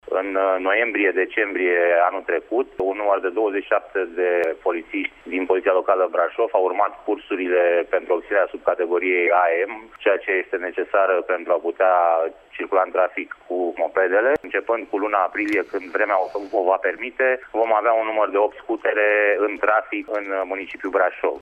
Din aprilie, opt dintre acestea vor circula pe străzile municipiului, a explicat, pentru Radio Tîrgu-Mureș, viceprimarul Brașovului, Ciprian Bucur: